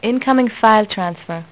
suono? Stai per ricevere un file...clicca sul "foglio" che lampeggia...